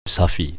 safi_prononciation.mp3